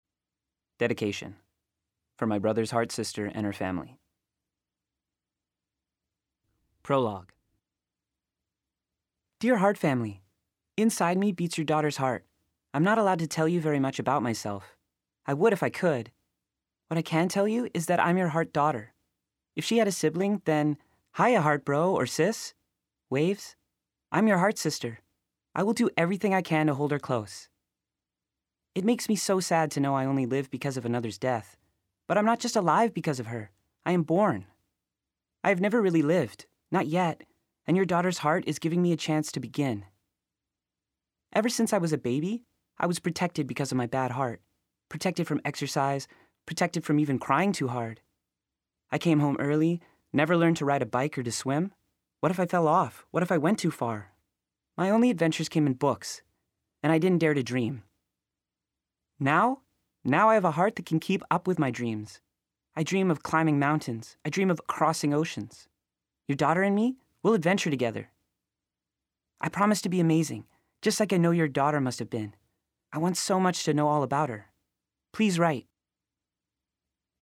Download the Heart Sister Audio Sample.